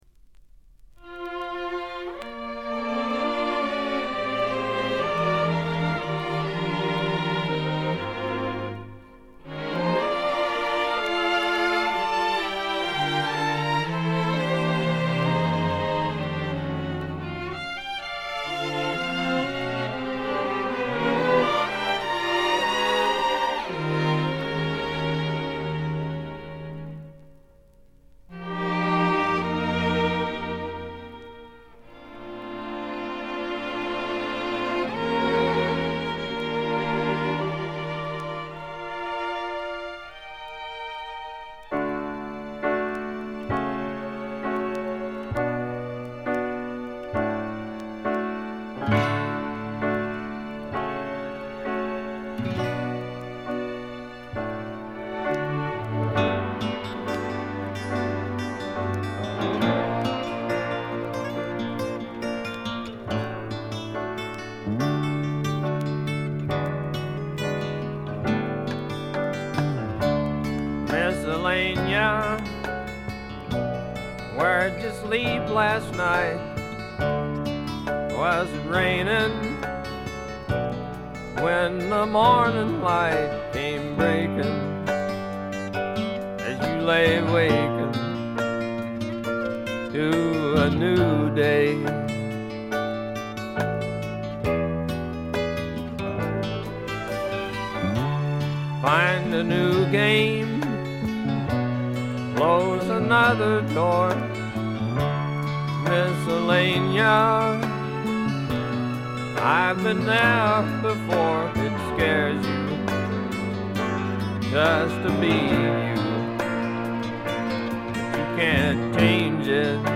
いかにもテキサス／ダラス録音らしいカントリー系のシンガー・ソングライター作品快作です。
ヴォーカルはコクがあって味わい深いもので、ハマる人も多いと思います。
試聴曲は現品からの取り込み音源です。